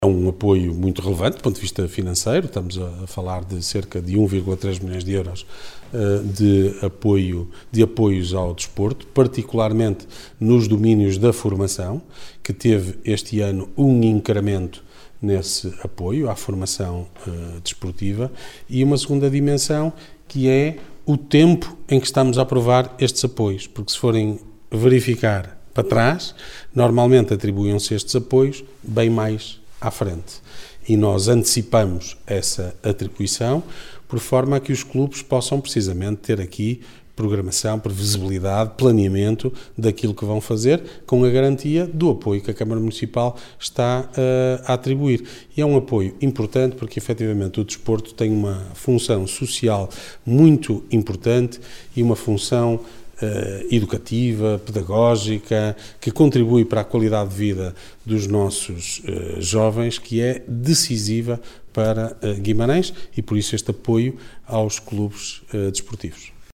Declarações de Ricardo Araújo, presidente da Câmara Municipal de Guimarães, a propósito da atribuição de apoios ao desporto.